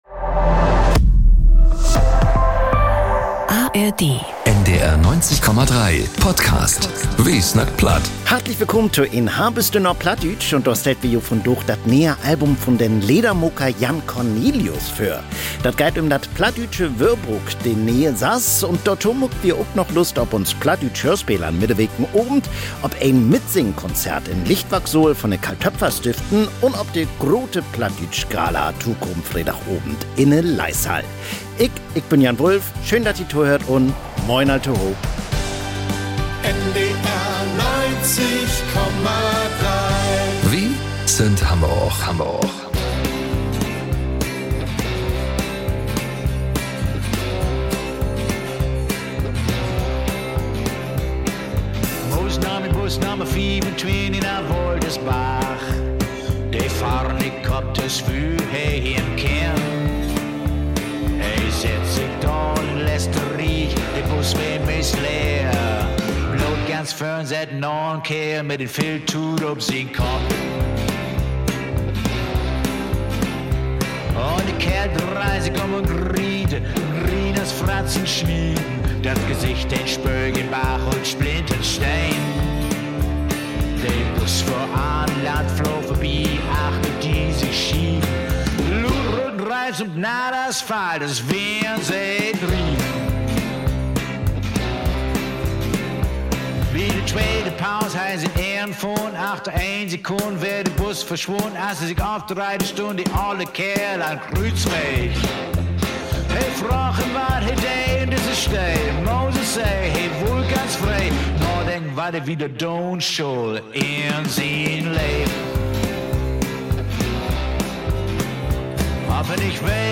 Umso schöner, dass sie mit mir isch a Tisch ghocket und so ehrlech u offe verzellt het! Mir redä übers "Guet Gnueg sii" i Fründ*inneschafte, was mer i all dene Jahr hei glehrt, was mer anenang schetzä u wieso mer o nach Toucher, geng no so äng u so töif wie no nie bef…